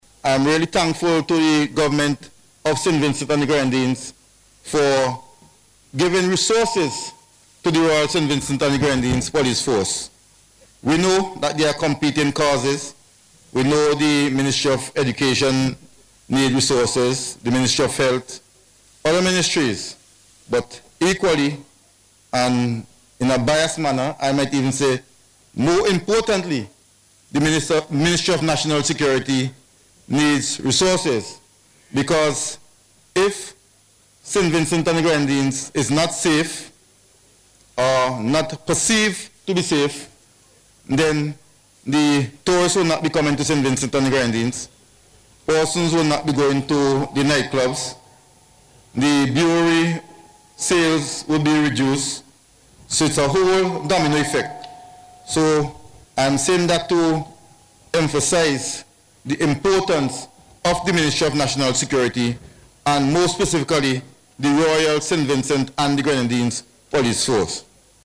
He was speaking at the 40th Passing Out Parade at the Victoria Park yesterday, where 113 Recruits became full-fledged Police Officers.